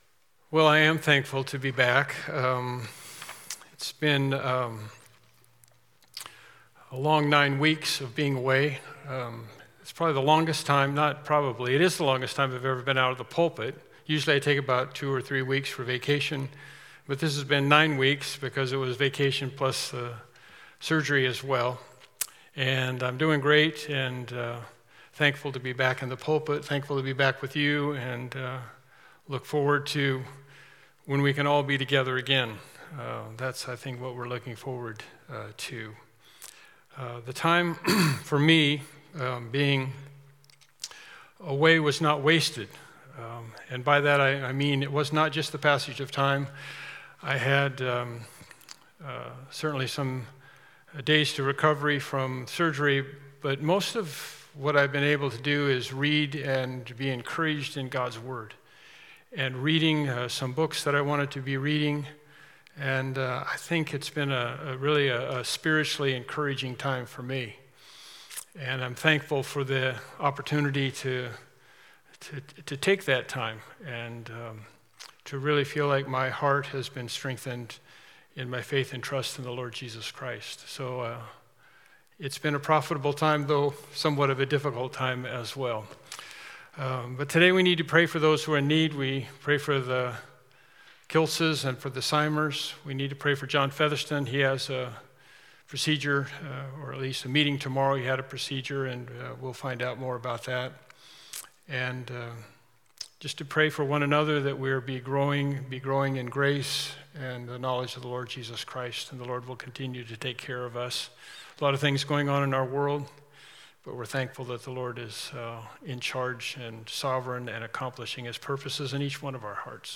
John 3 Service Type: Morning Worship Service Topics